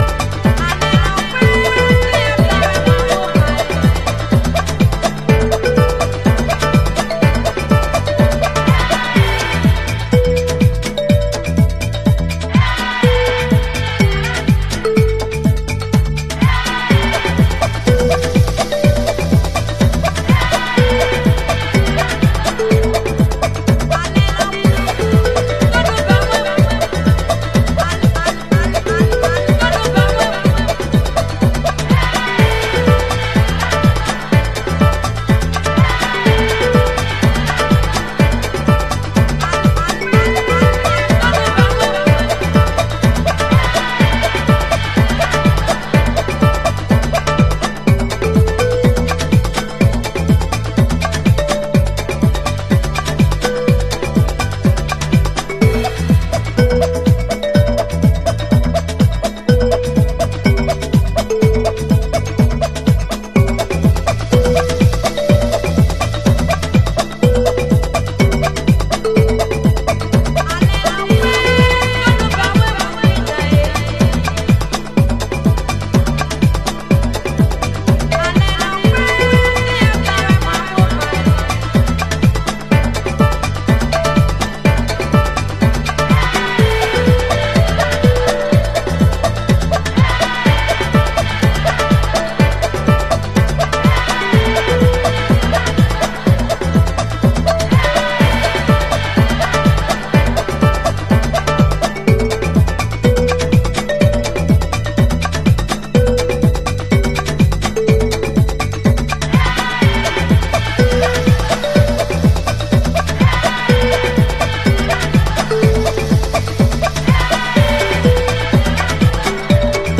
Chicago Oldschool / CDH
軽やかなアフリカン・パーカションにキッズ・チャントとくれば、コレは鉄板でしょう。